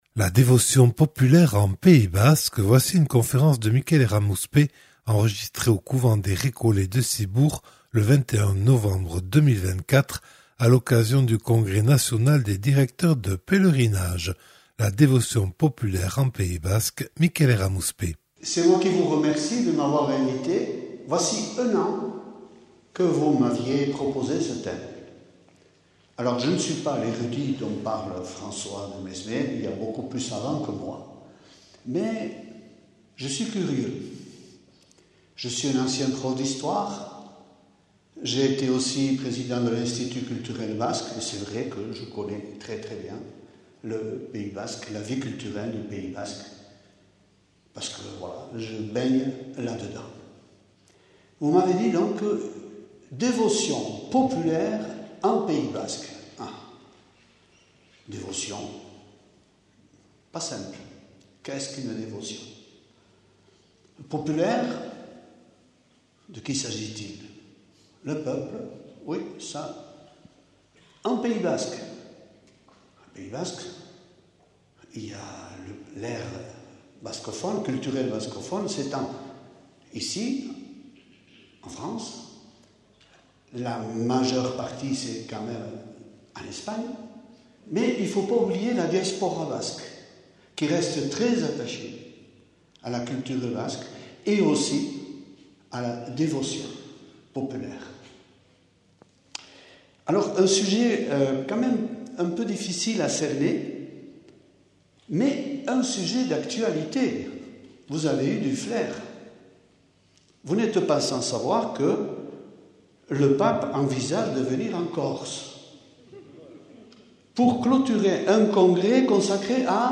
Une conférence
au couvent des Récollets de Ciboure à l’occasion du Congrès national des directeurs de pèlerinage.